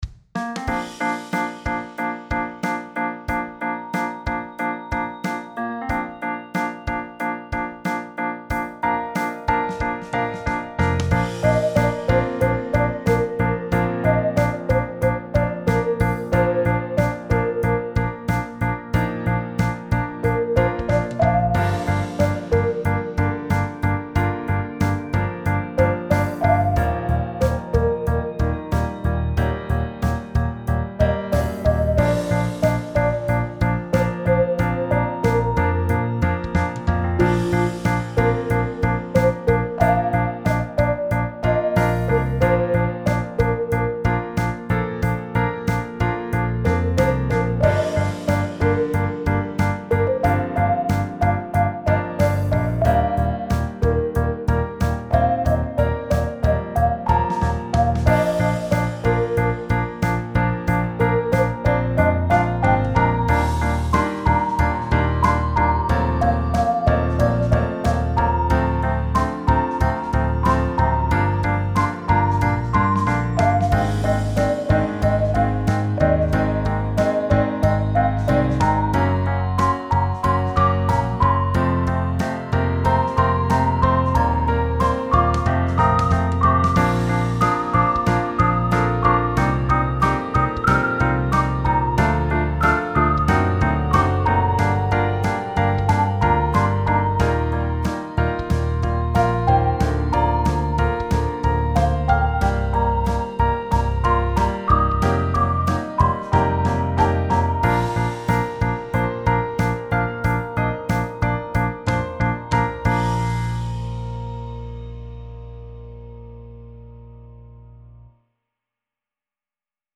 未分類 みなぎる力 夕焼け 懐かしい 明るい 音楽日記 よかったらシェアしてね！